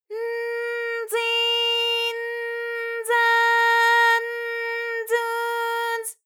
ALYS-DB-001-JPN - First Japanese UTAU vocal library of ALYS.
z_n_zi_n_za_n_zu_z.wav